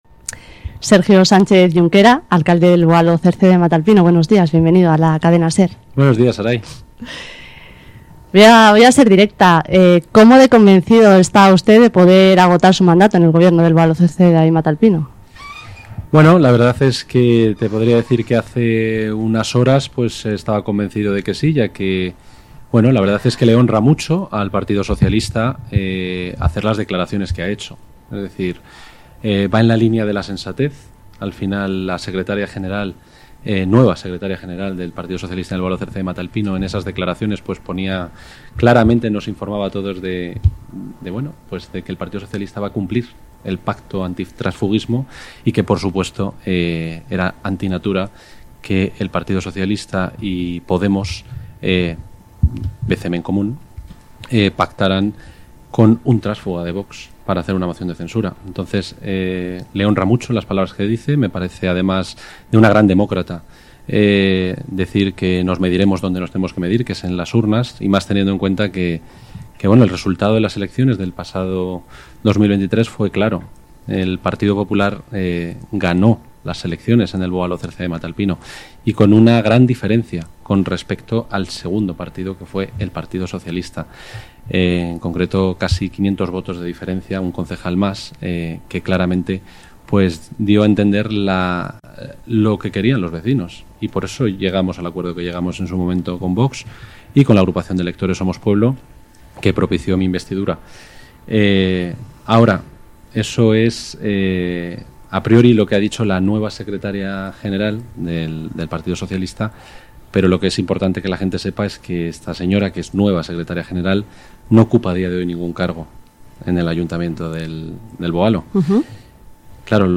Hoy por hoy Madrid Sierra 09/10/2025 – Entrevista a Sergio Sánchez Yunquera, alcalde de BOCEMA, sobre una posible moción de censura en el municipio